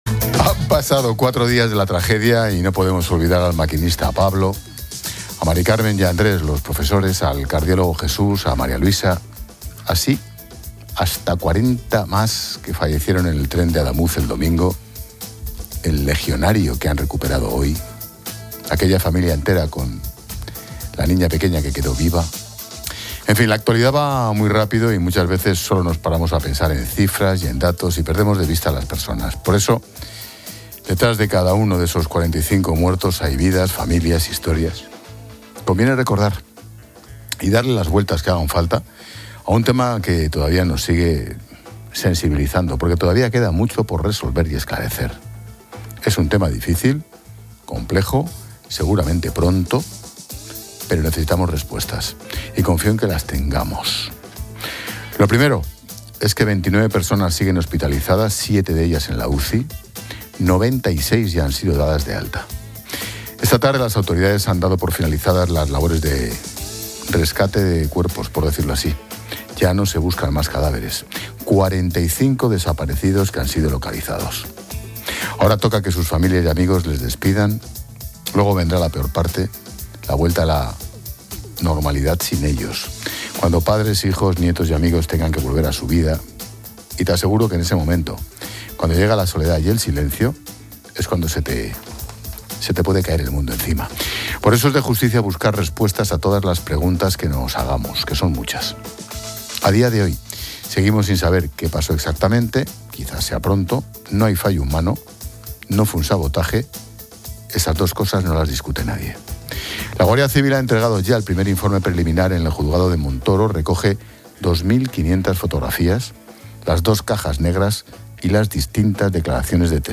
En la tertulia del programa